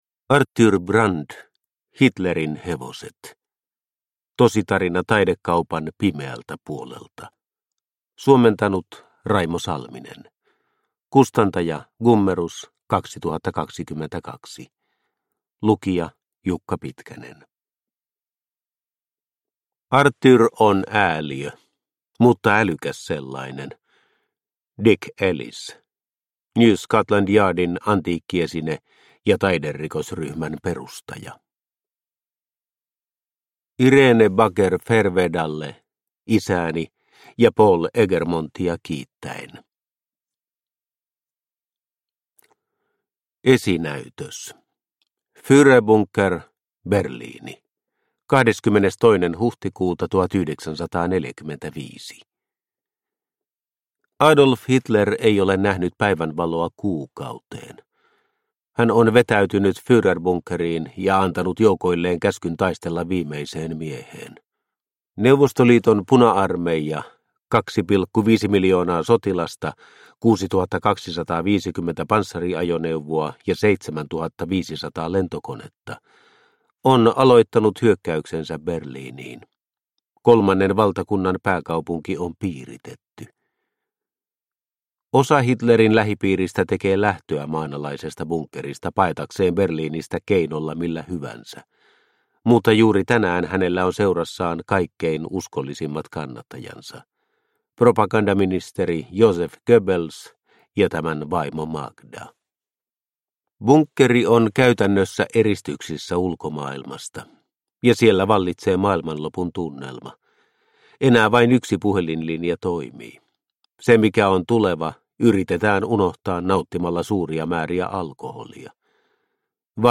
Hitlerin hevoset – Ljudbok – Laddas ner